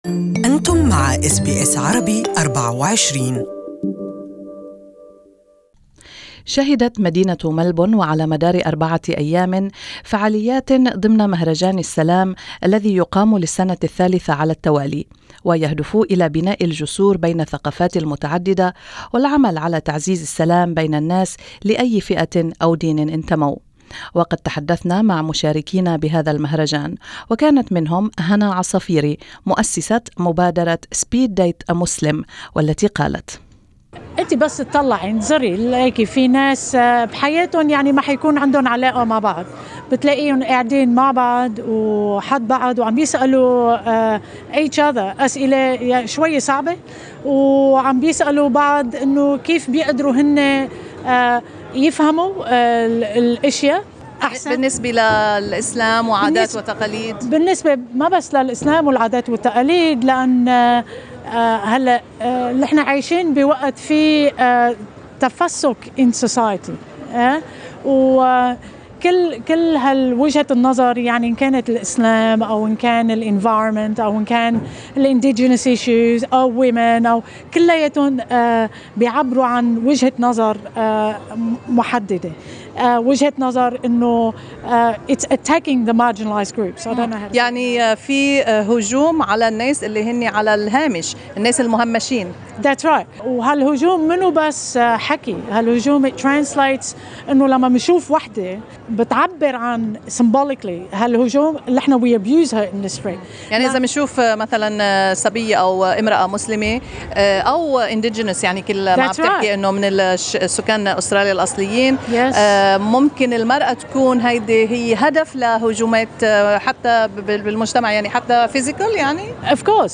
Interviews conducted at the Festival of Peace SalamFest that was held in Melbourne between 25 and 28 of October 2018.